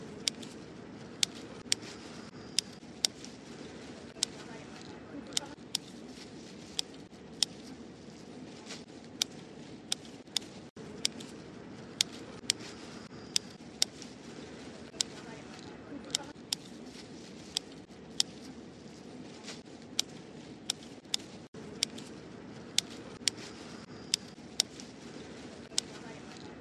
Tiếng dùng kéo, móng tay Cắt rau, Ngắt hái rau củ quả cật cật…
Tiếng dùng dao, lưỡi liềm, tay… thu hoạch rau củ quả sột soạt Tiếng Ngắt rau, Hái rau củ quả…
Thể loại: Tiếng động
Description: Tiếng dùng kéo, móng tay cắt rau, ngắt hái rau củ quả cật cật... tái hiện rõ nét tiếng kéo cắt giòn giã, tiếng ngón tay tách rau nhanh gọn và tiếng rau củ bị bứt khỏi cành nghe "đã tai".
tieng-dung-keo-mong-tay-cat-rau-ngat-hai-rau-cu-qua-cat-cat-www_tiengdong_com.mp3